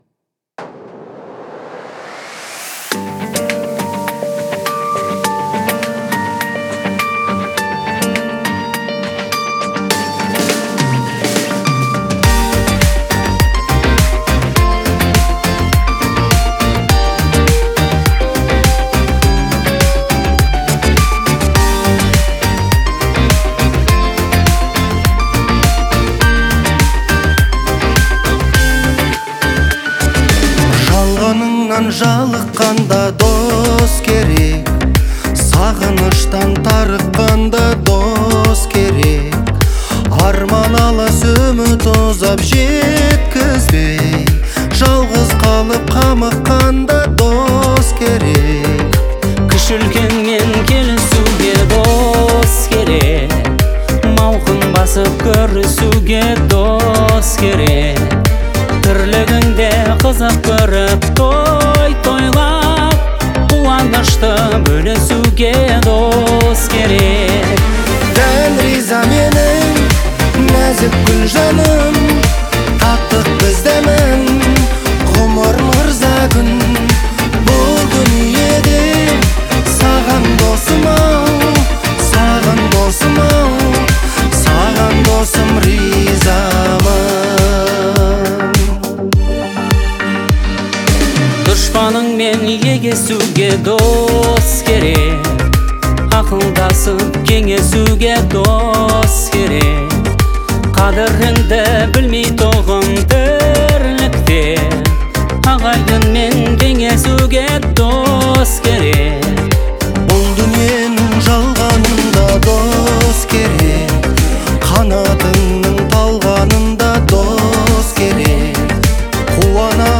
это душевная казахская песня в жанре поп